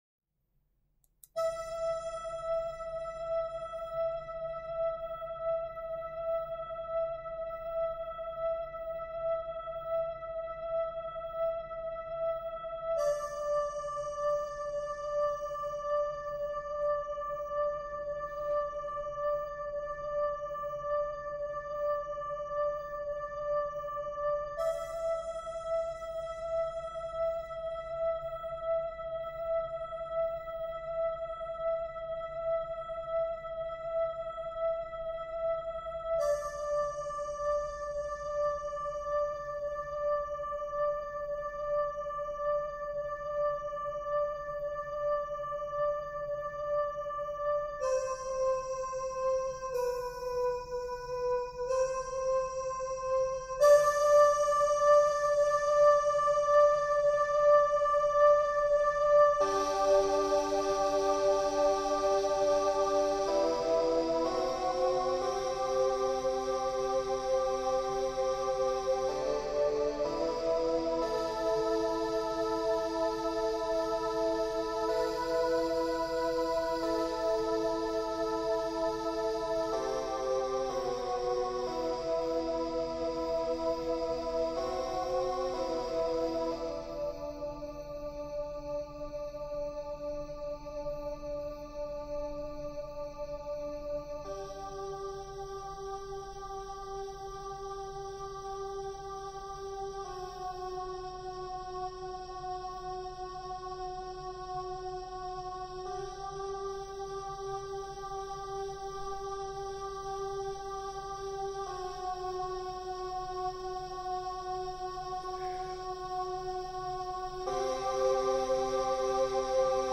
\set Staff.midiInstrument = "synth voice"
\set Staff.midiInstrument = "pad 4 (choir)"
\new Voice { \key g\major \time 3/2 \tempo 4 = 62